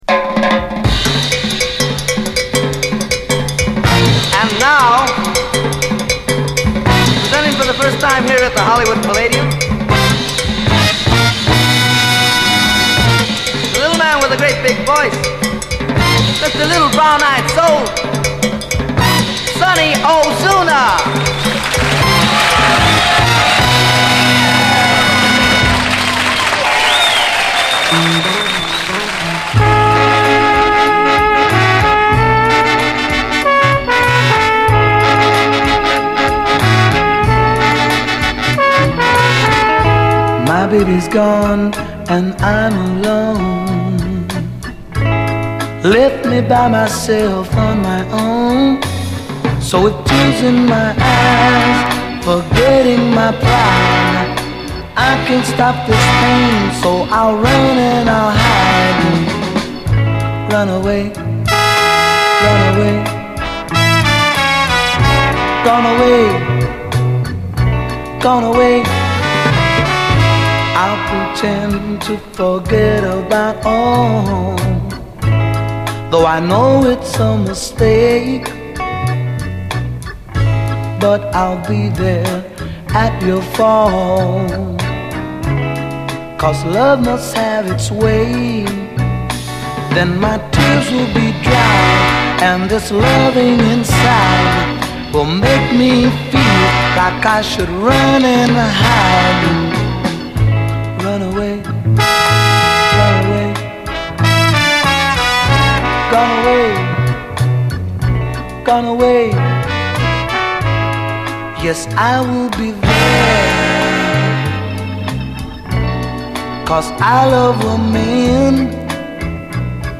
SOUL, 60's SOUL, LATIN
ほとばしる哀愁が並ではない最強のチカーノ・ソウル・クラシック